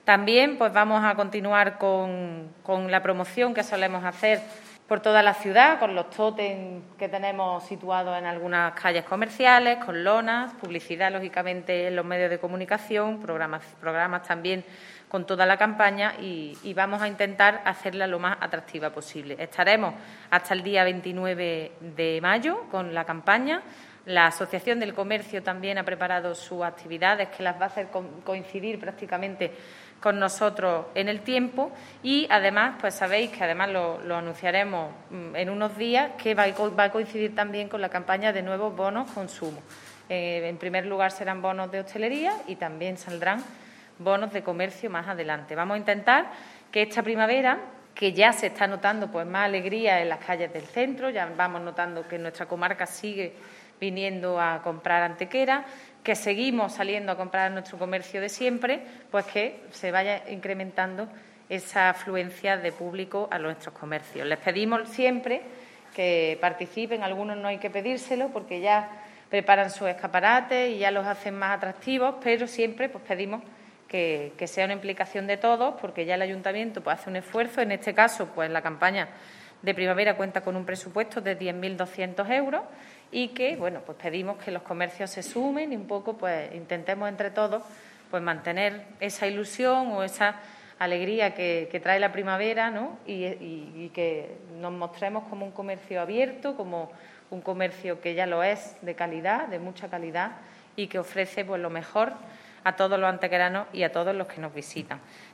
La teniente de alcalde delegada de Turismo, Patrimonio Histórico, Políticas de Empleo y Comercio, Ana Cebrián, ha presentado hoy en rueda de prensa la Campaña de Primavera con la que se trata de impulsar, desde el Ayuntamiento, el comercio de nuestra ciudad durante las próximas semanas coincidiendo con el inicio y transcurso de la nueva estación.
Cortes de voz